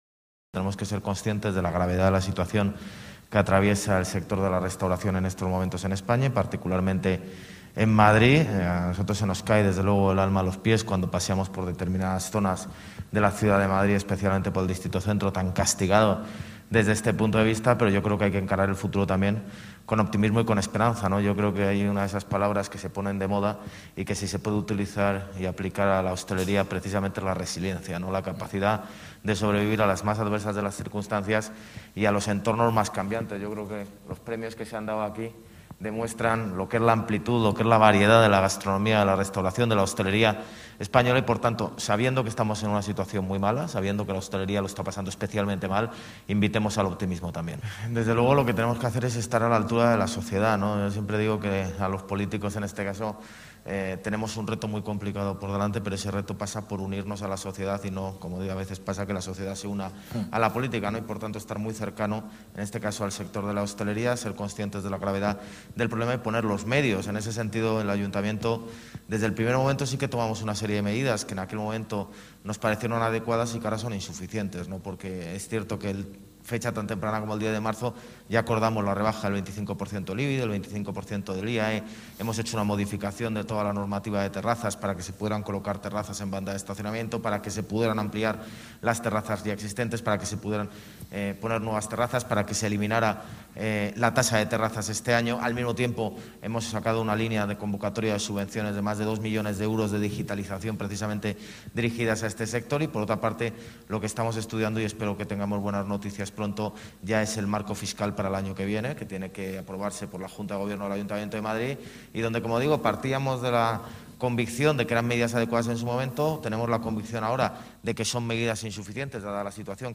En la XIV Edición de los Premios Nacionales de Hostelería, junto a la vicealcaldesa, Begoña Villacís y la ministra de Industria, Turismo y Comercio, Reyes Maroto
Nueva ventana:José Luis Martínez-Almeida, alcalde de Madrid